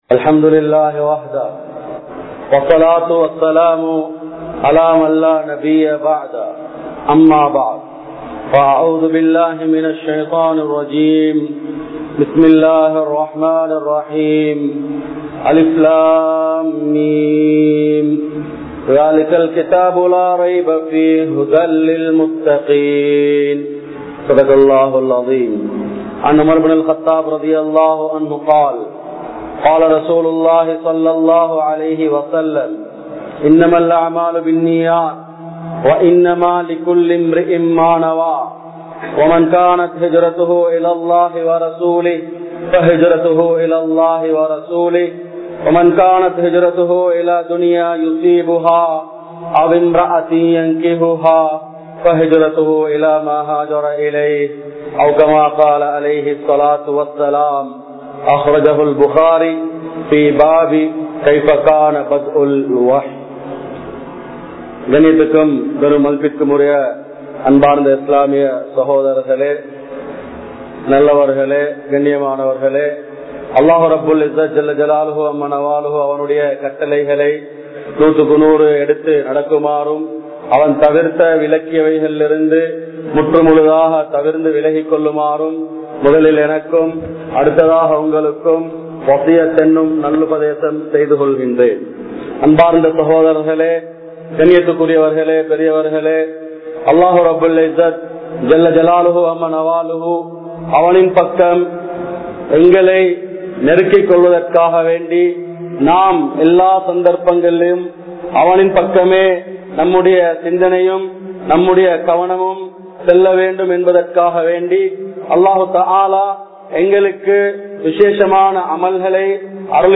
Dhull Hijjavin Aarampa 10 Naatkalin Sirappuhal (துல்ஹிஜ்ஜாவின் ஆரம்ப 10 நாட்களின் சிறப்புகள்) | Audio Bayans | All Ceylon Muslim Youth Community | Addalaichenai
Kollupitty Jumua Masjith